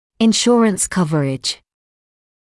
[ɪn’ʃuərəns ‘kʌvərɪʤ][ин’шуэрэнс ‘кавэридж]страховое покрытие